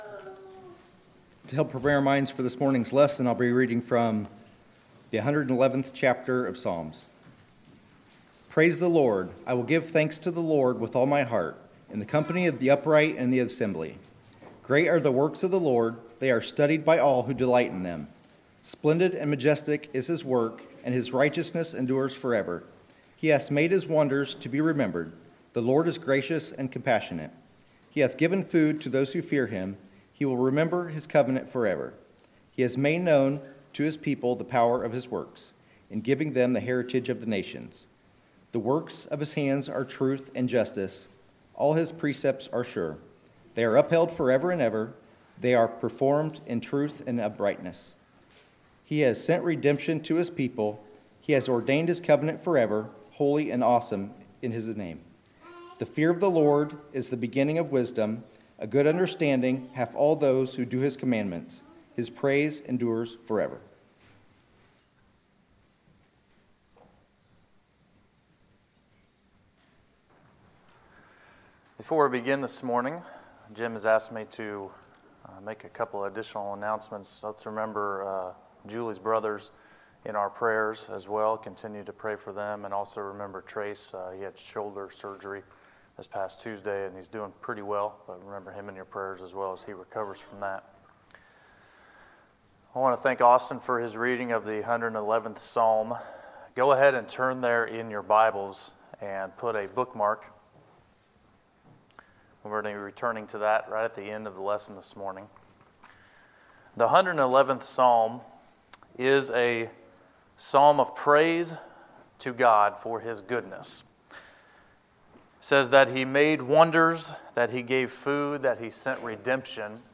Sermons, November 25, 2018